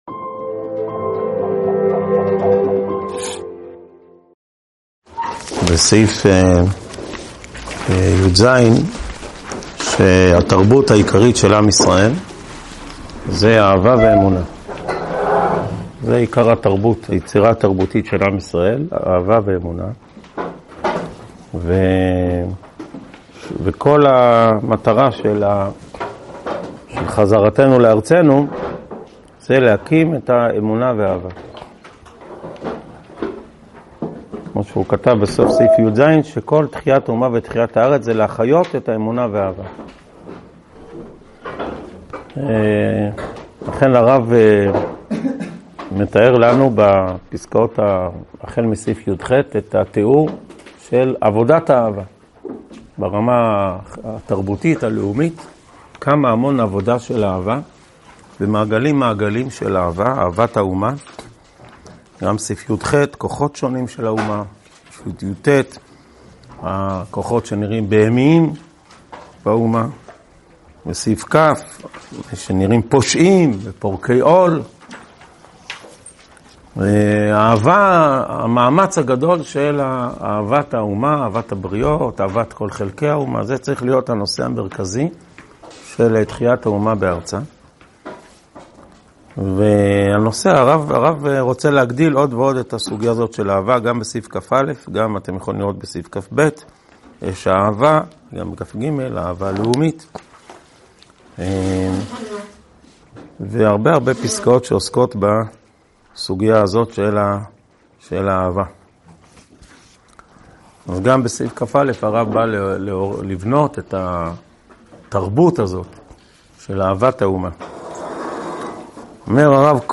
שיעור 28 מתוך 59 בסדרת אורות התחיה
הועבר בישיבת אלון מורה בשנת תשפ"ד.